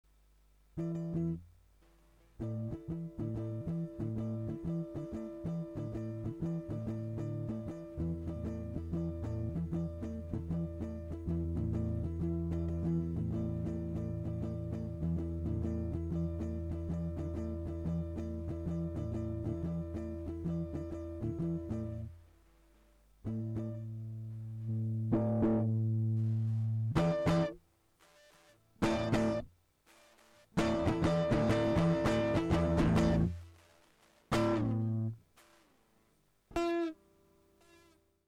ほうが良いのか、ハムピックアップ・セレクター音の変化具合は、 の